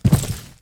FallImpact_Concrete 01.wav